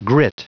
Prononciation du mot grit en anglais (fichier audio)
Prononciation du mot : grit